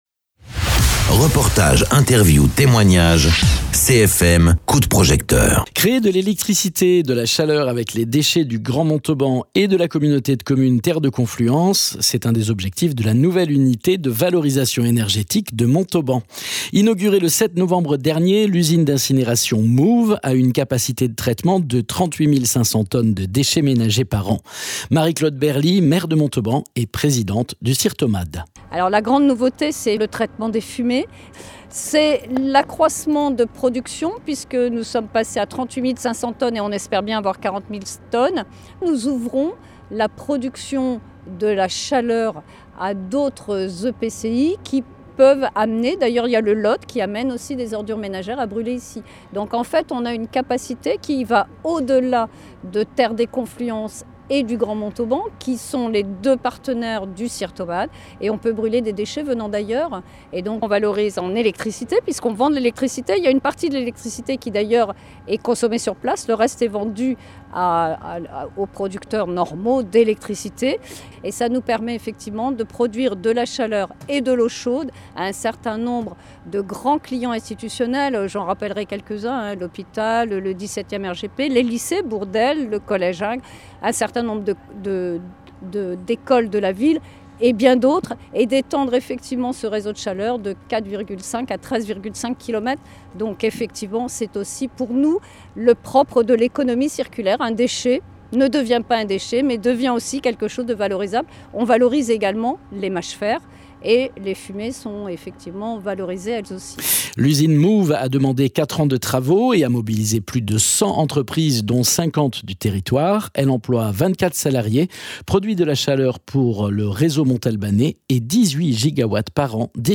Interviews
Invité(s) : MC Claude Berly, maire de Montauban et présidente du SIRTOMAD